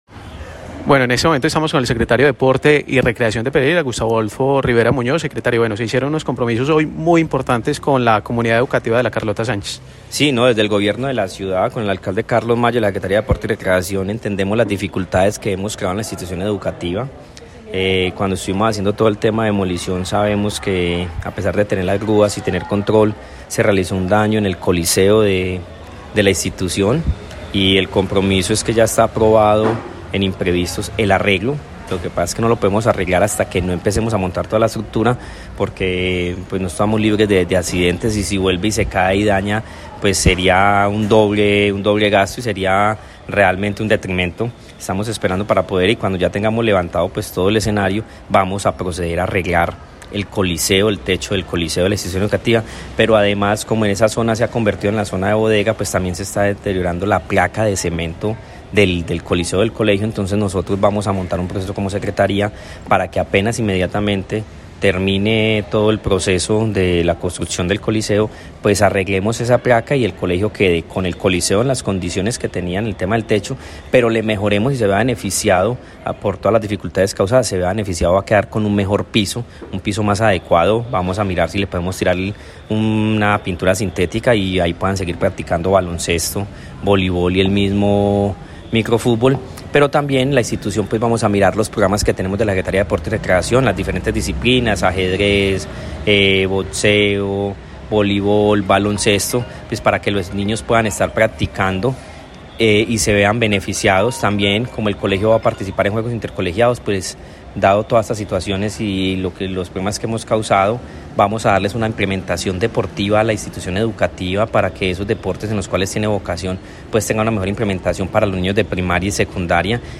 Audio_secretaria_de_deporte_Conversacion_IE_Carlota_Sanchez.mp3